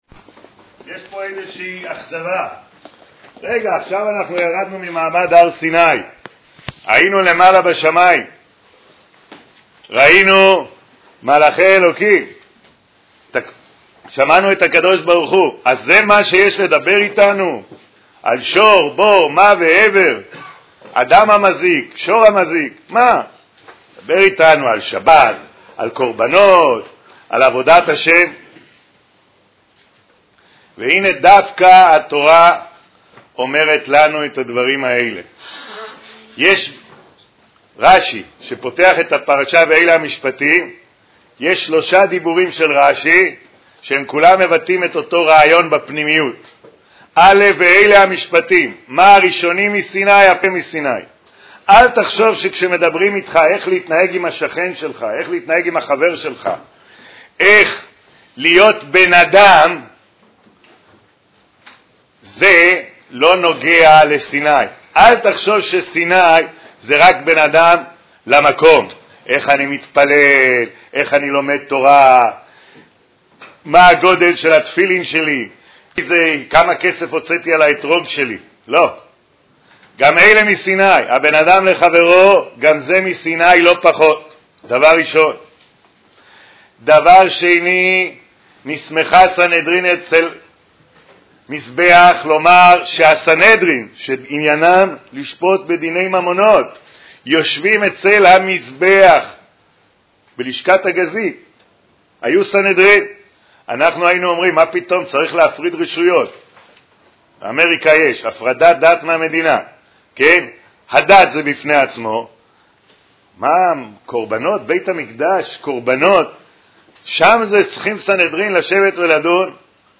Listen to the Shiur: Listening to shiurim on this website requires the Windows Media Player.